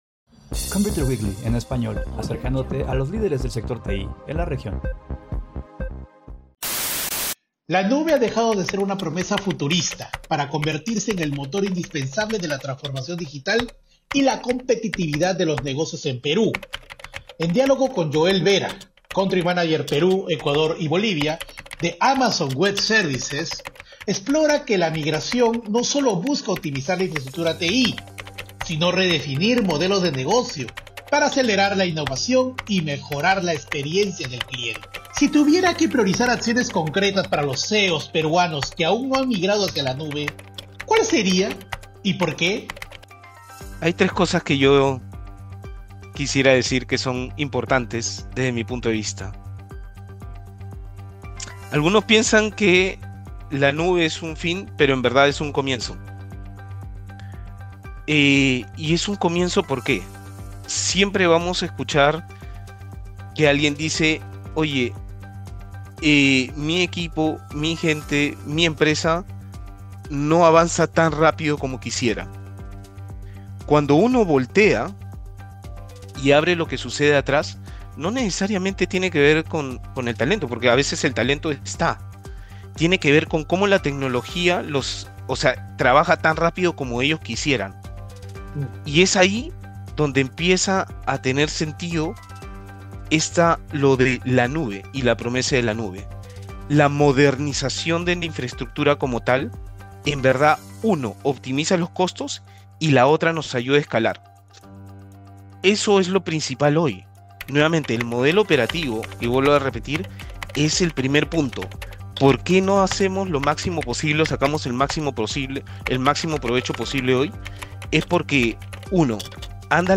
Sobre este aspecto, comenta en entrevista con ComputerWeekly en Español, el desafío para los CEO peruanos no es técnico, en primera instancia: es organizacional y cultural.